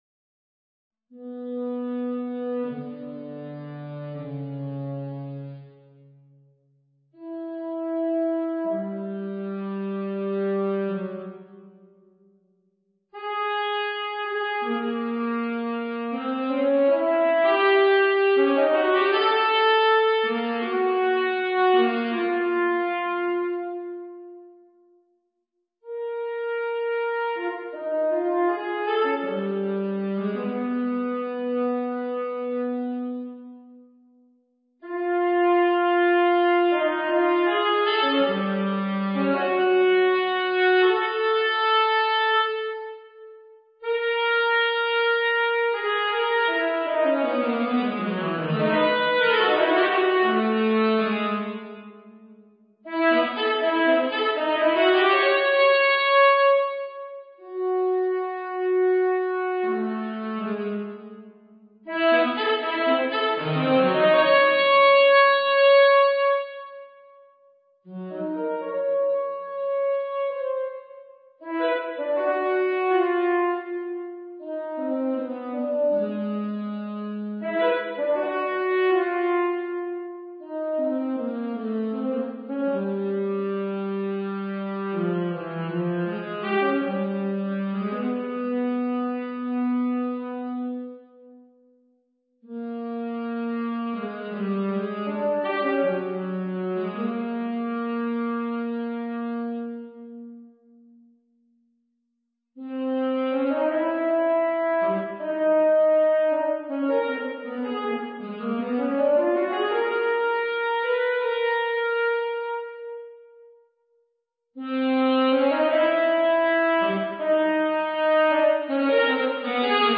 Solo for Tenor Saxophone,
MP3  Played by Sibelius 7,5/Garritan Sounds